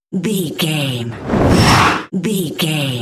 Whoosh fast jet bright
Sound Effects
Fast
futuristic
intense
whoosh